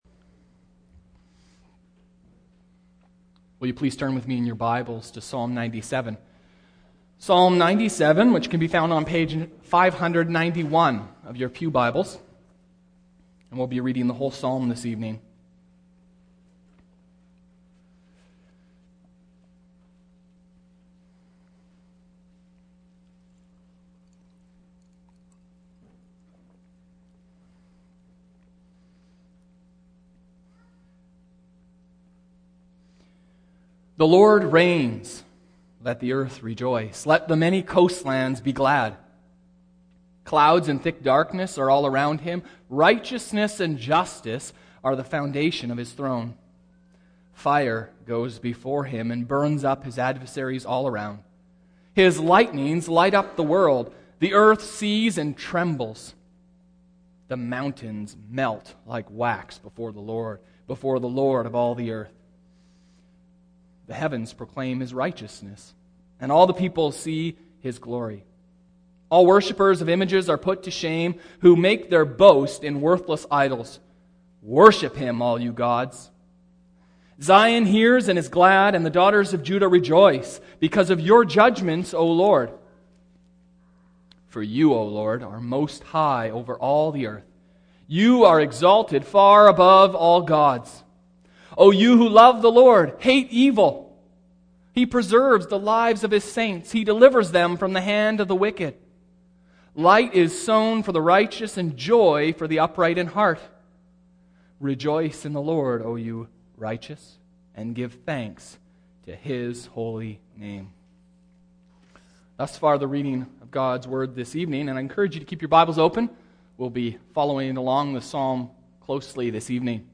The Lord Reigns (technical issues in audio)